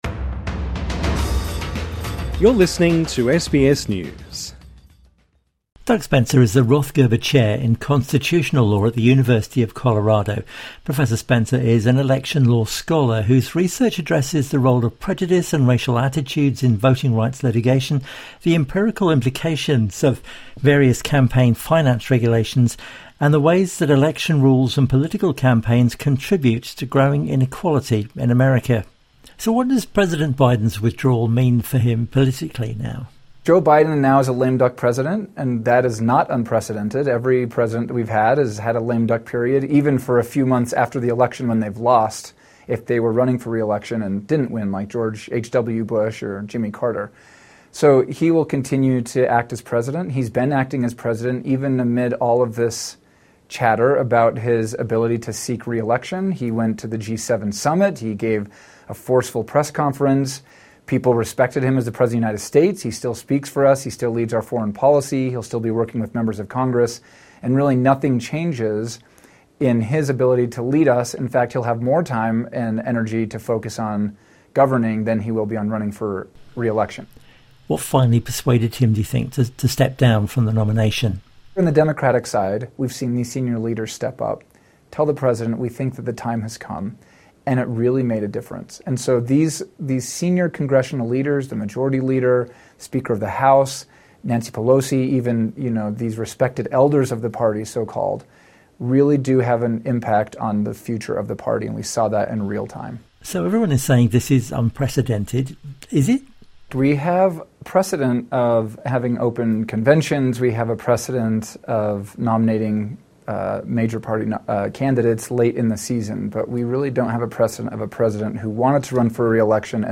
INTERVIEW: US Political expert on Kamala Harris' potential run for the US Presidency